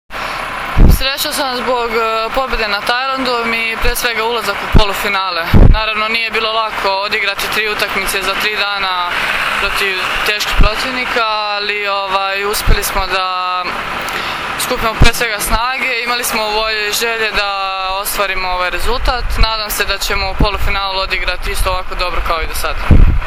IZJAVA ANE ANTONIJEVIĆ